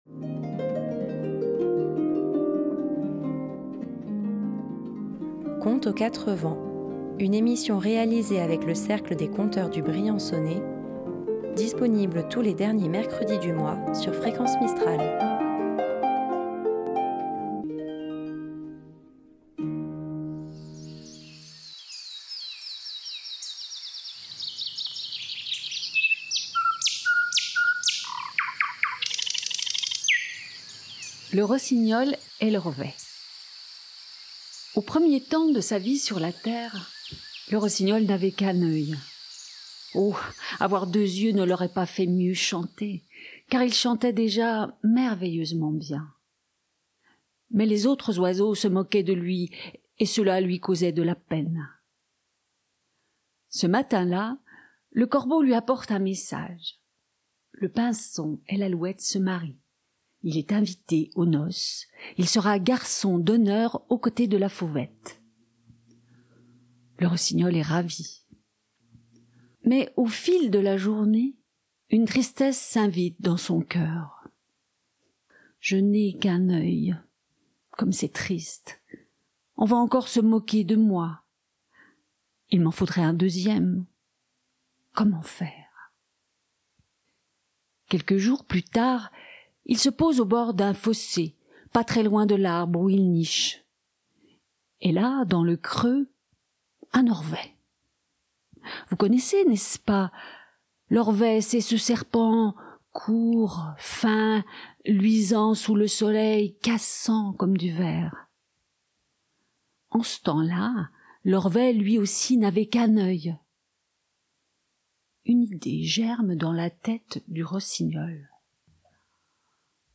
Deux voix, deux styles, deux ambiances...